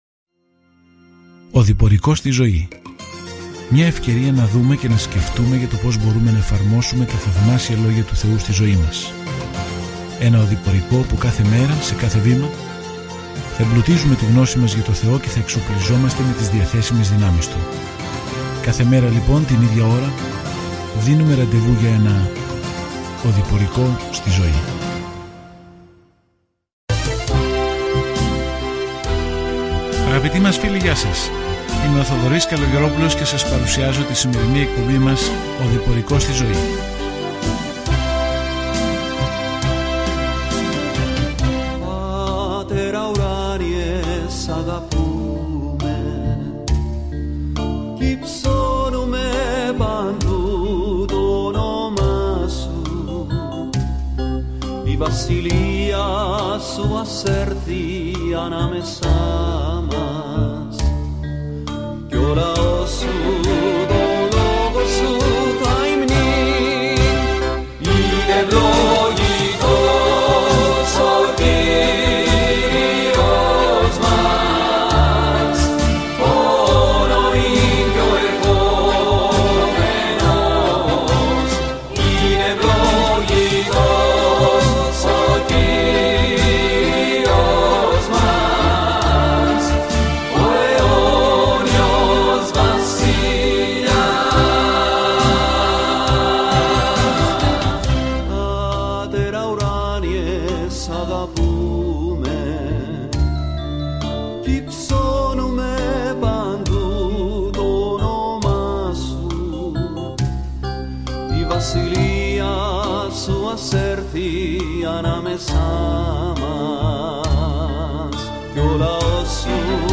Κείμενο ΑΡΙΘΜΟΙ 3 ΑΡΙΘΜΟΙ 4 ΑΡΙΘΜΟΙ 5 ΑΡΙΘΜΟΙ 6 ΑΡΙΘΜΟΙ 7 Ημέρα 1 Έναρξη αυτού του σχεδίου Ημέρα 3 Σχετικά με αυτό το σχέδιο Στο βιβλίο των Αριθμών, περπατάμε, περιπλανιόμαστε και λατρεύουμε με τον Ισραήλ στα 40 χρόνια στην έρημο. Καθημερινά ταξιδεύετε στους Αριθμούς καθώς ακούτε την ηχητική μελέτη και διαβάζετε επιλεγμένους στίχους από τον λόγο του Θεού.